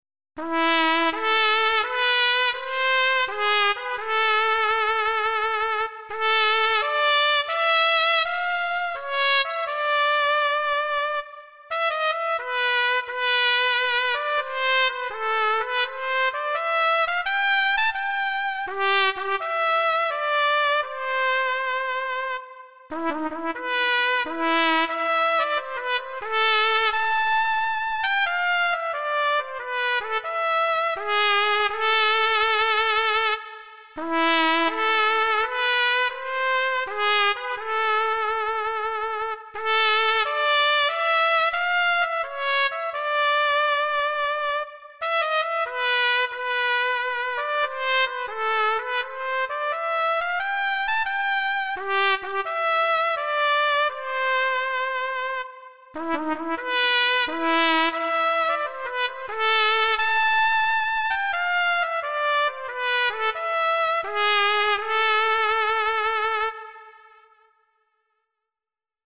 Bu Trumpet sesi gerçeğe çok yakın olduğu için İstiklal Maşımızı çalmak istedim ve bu ses çok yakışdı diye düşünüyorum. Ufak tefek hatalar olursa affola...